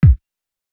KICKPUNCH5.wav